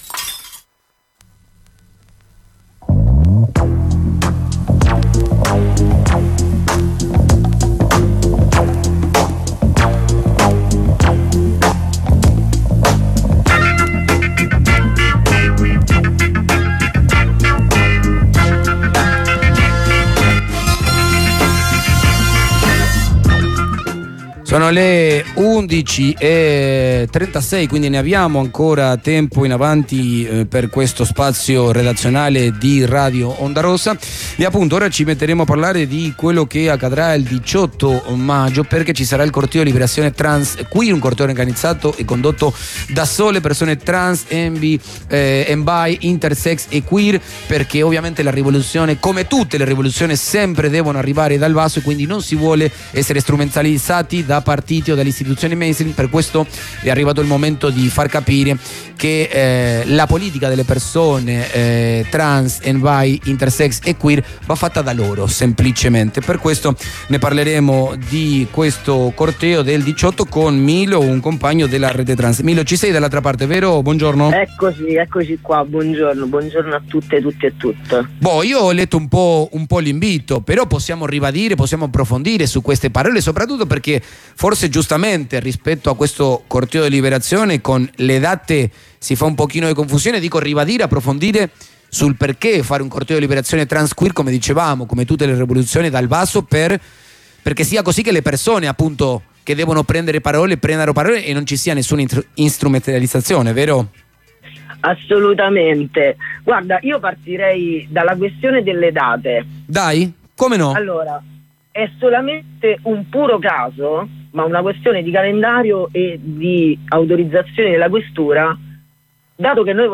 Comunicazione telefonica